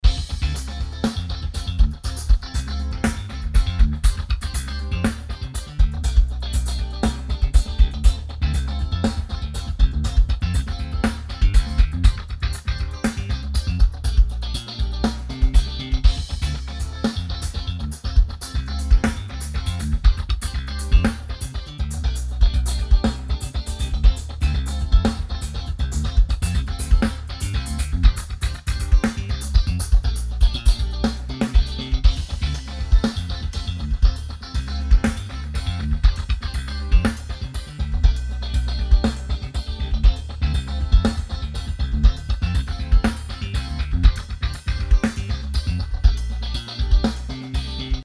Игрался тут с одним плагином, записал минидемку. Слушать строго в ушах и поставить на loop.
Будет ощущение, что вы перед мониторами медленно крутитесь на кресле.